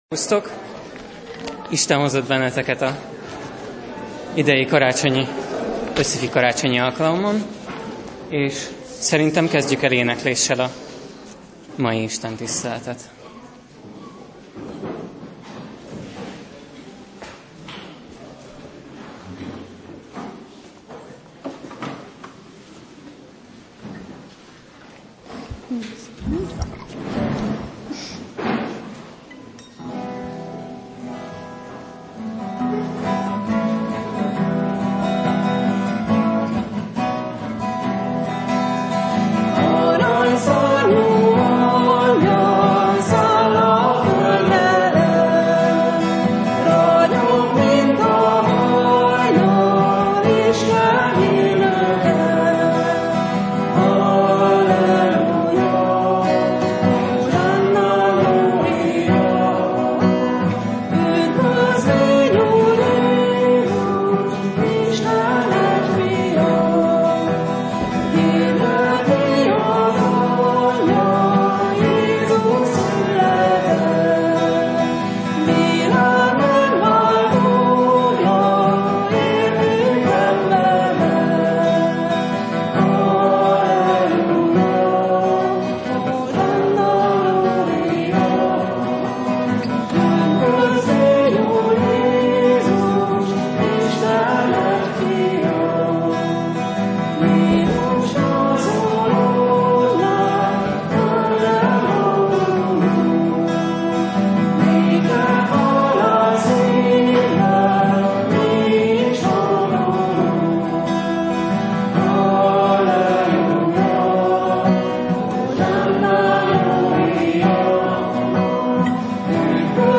A karácsonyi ifjúsági istentisztelet hangfelvétele letölthet? fent. A felvétel vágatlan, minden elhangzott történést tartalmaz.